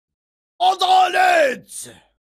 garen-adalet-ses-efekti.mp3